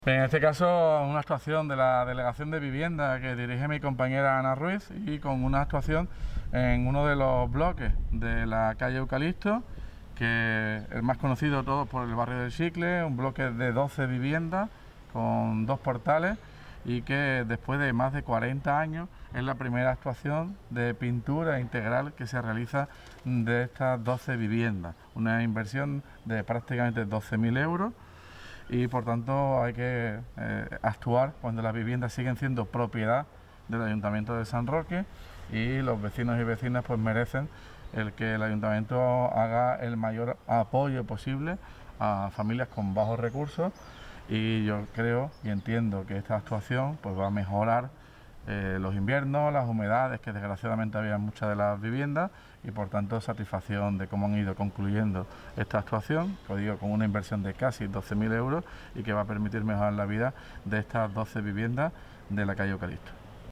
PINTURA FACHADAS BLOQUES CALLE EUCALIPTO TOTAL ALCALDE.mp3